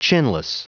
Prononciation du mot chinless en anglais (fichier audio)
Prononciation du mot : chinless